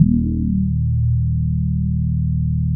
27 BASS   -L.wav